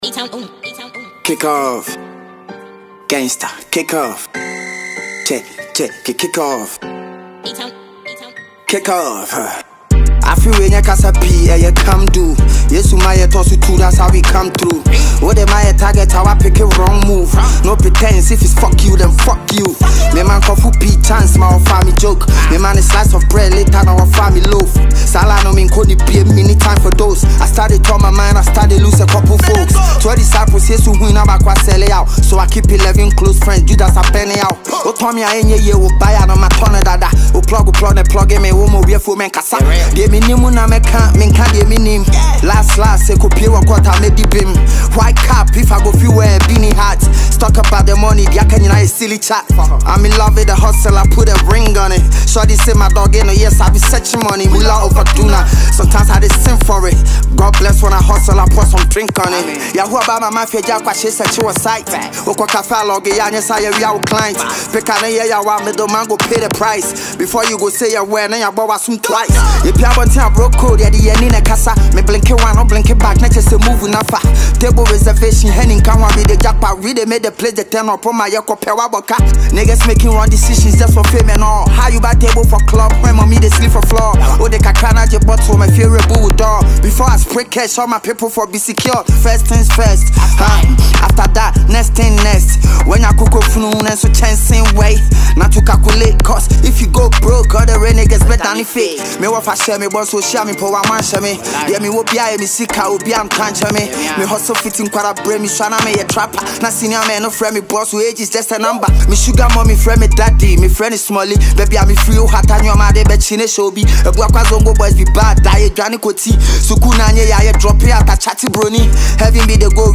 a Ghanaian rapper